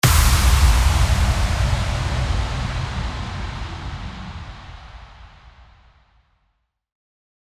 FX-1787-IMPACT
FX-1787-IMPACT.mp3